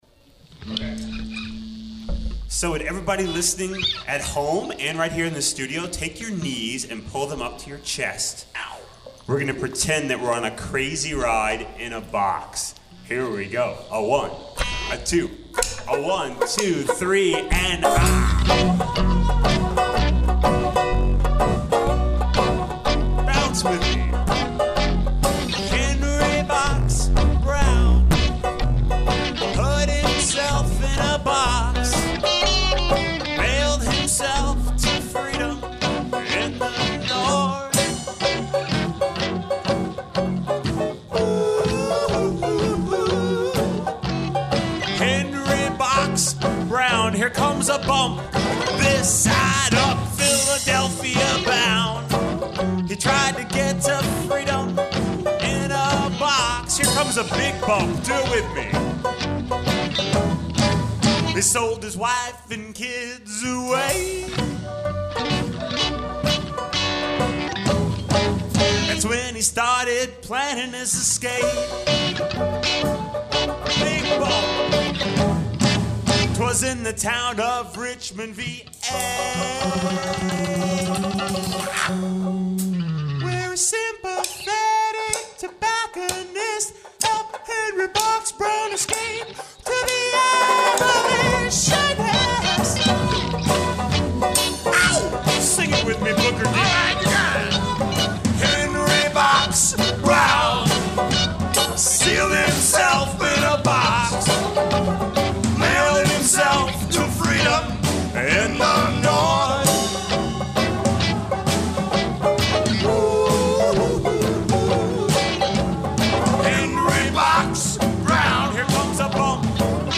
educational rock band for kids
Hear them live in our studios.